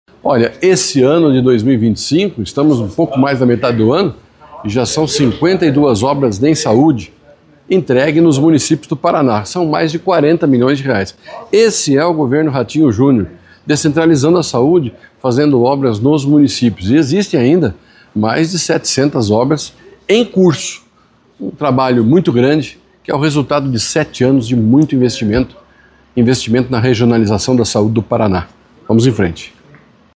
Sonora do secretário da Saúde, Beto Preto, sobre a conclusão de 52 obras de saúde neste ano